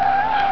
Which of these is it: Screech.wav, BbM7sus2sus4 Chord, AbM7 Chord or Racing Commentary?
Screech.wav